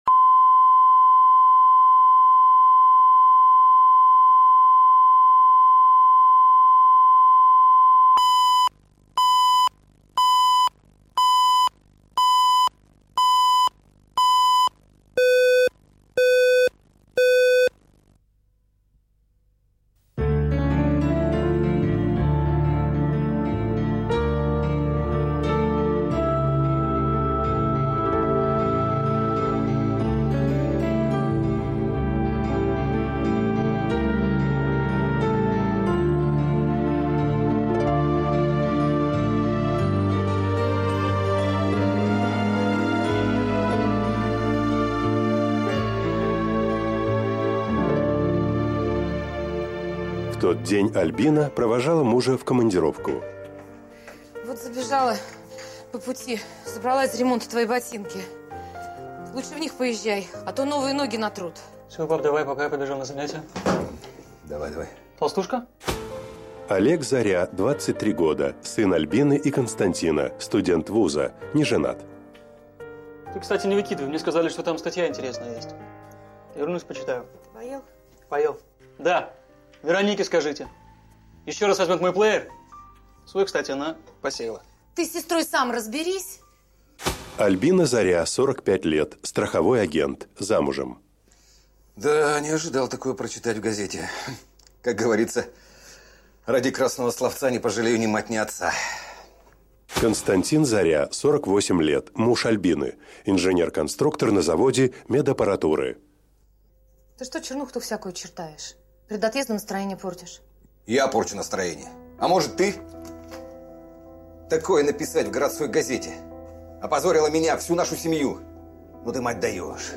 Аудиокнига Сор из избы | Библиотека аудиокниг